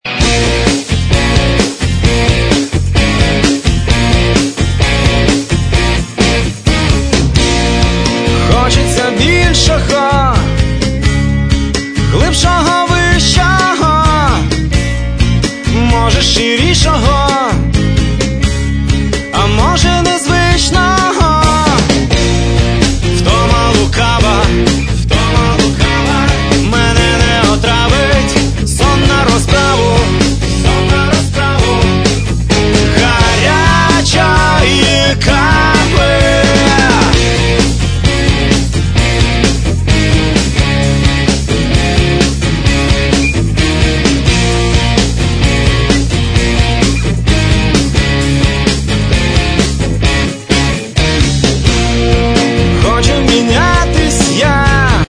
Початок » CDs» Рок Мій аккаунт  |  Кошик  |  Замовити